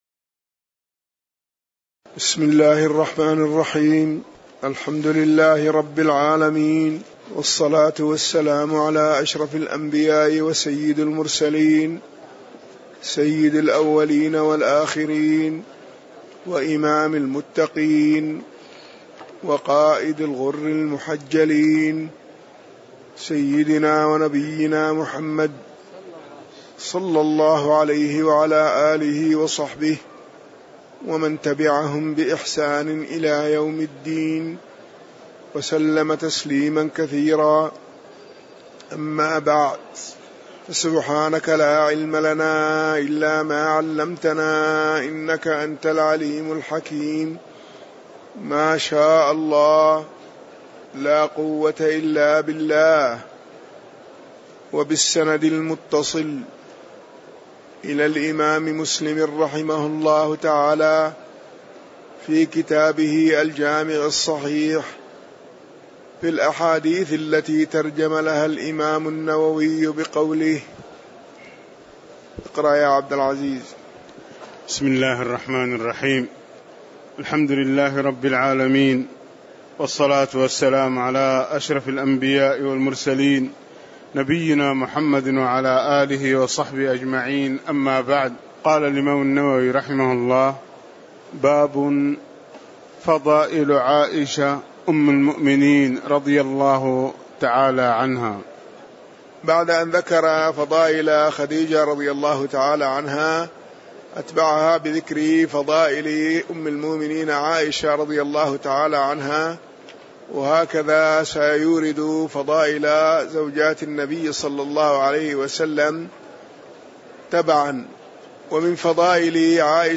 تاريخ النشر ٢٩ شعبان ١٤٣٧ هـ المكان: المسجد النبوي الشيخ